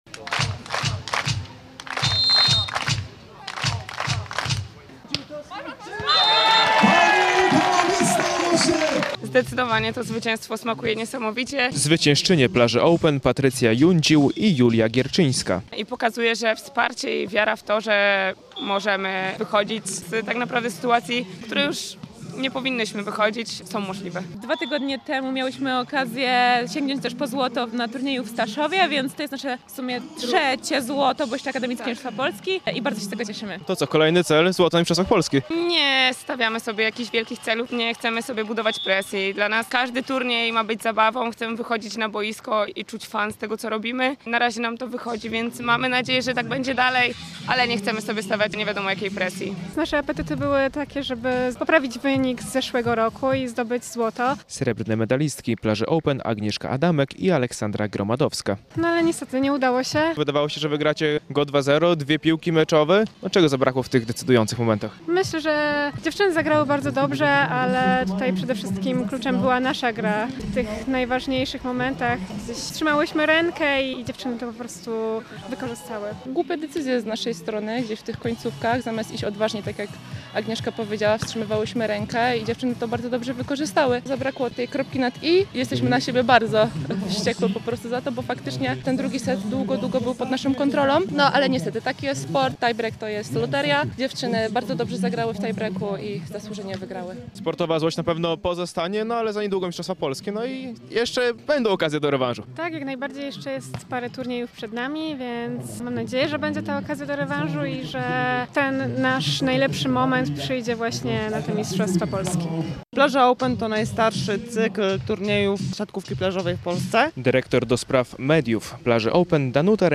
Plaża Open w Białymstoku - relacja